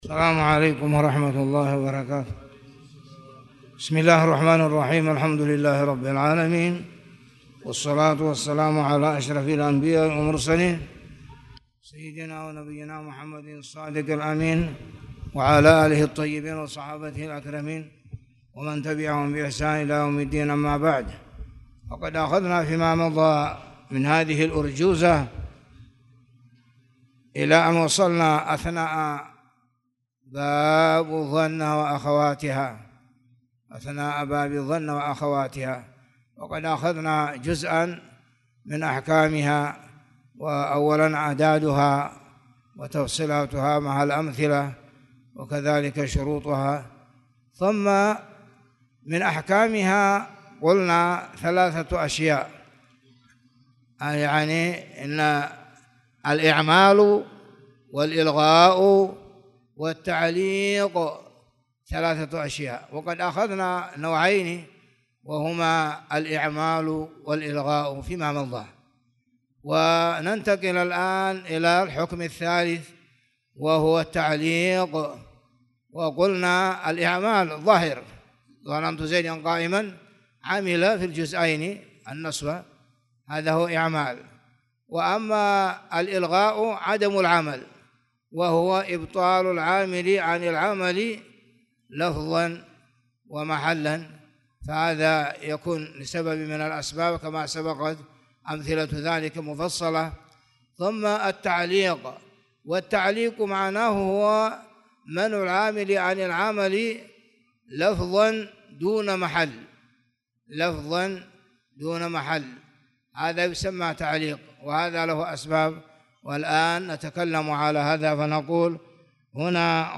تاريخ النشر ١٦ صفر ١٤٣٨ هـ المكان: المسجد الحرام الشيخ